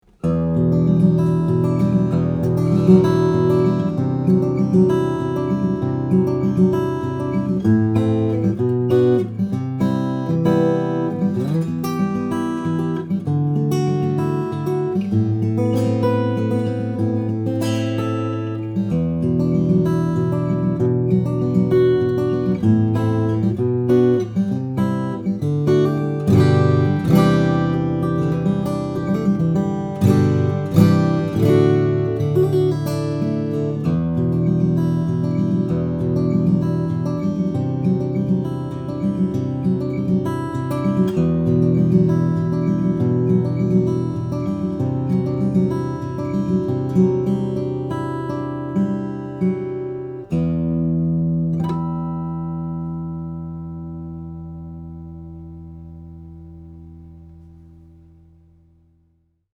New Avalon L320B Indian/Sitka Made In Ireland!
The Avalon L-320B comes from their Arc line of guitars and features an Armrest Bevel and Demi Cutaway for superb comfort and playability.